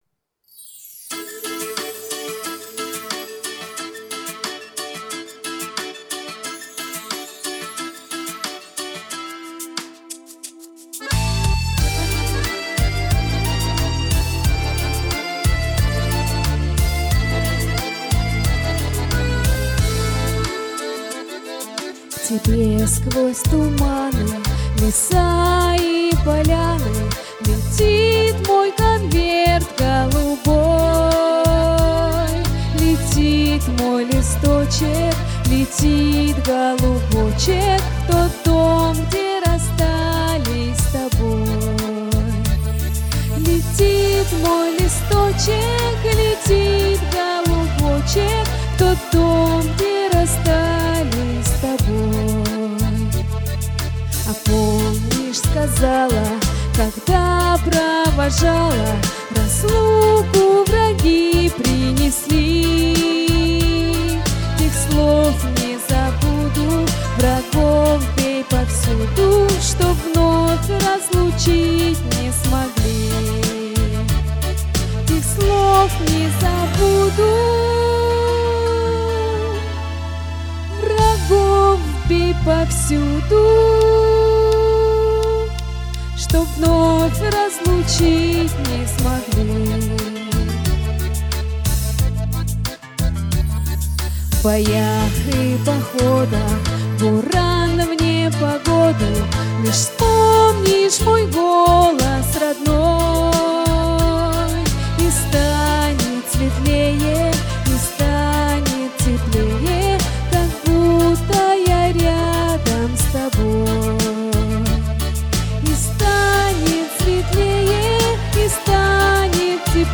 караоке
теги: конверт, военные песни, День победы, минус